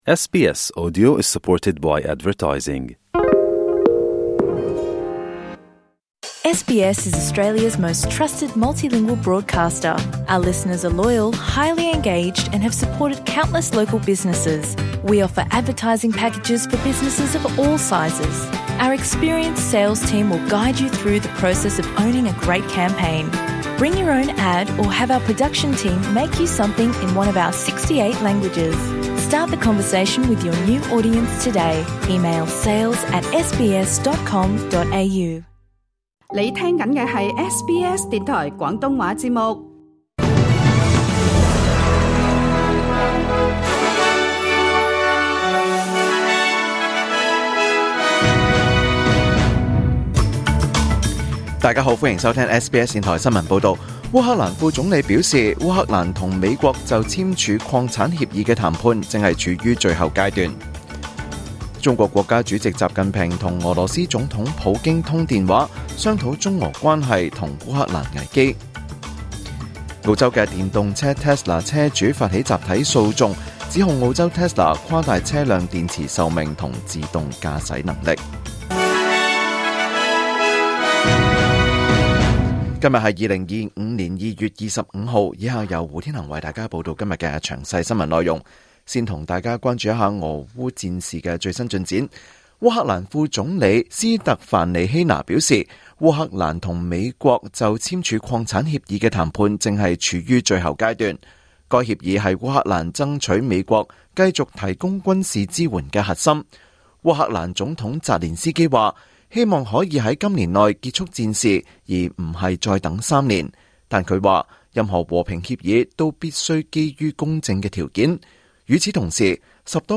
2025 年 2 月 25 日 SBS 廣東話節目詳盡早晨新聞報道。